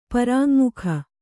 ♪ parāŋmukha